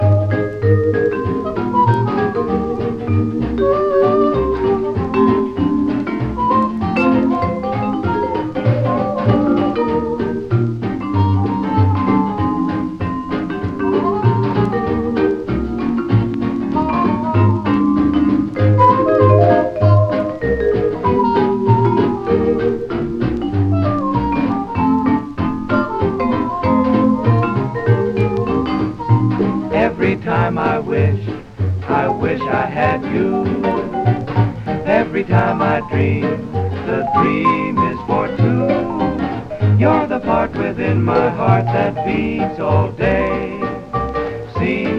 Jazz　Sweden　12inchレコード　33rpm　Mono